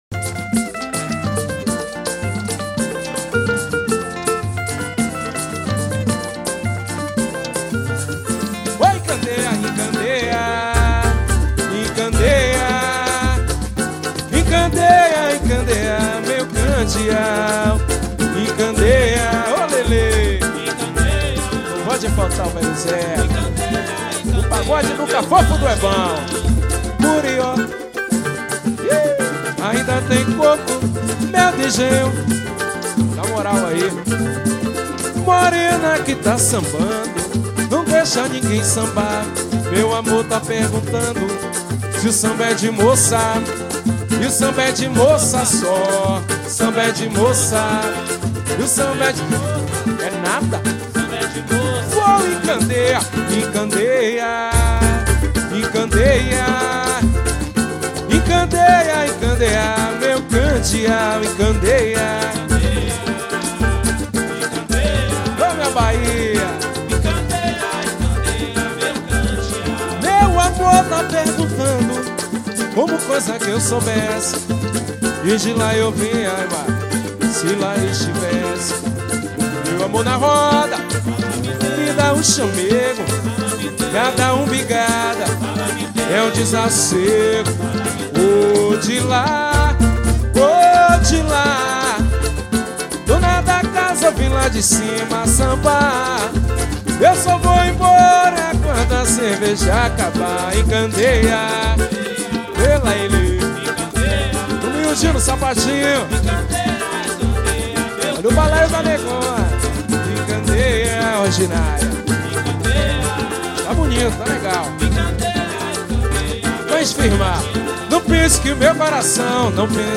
Samba.